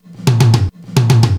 TOM FILL 1-R.wav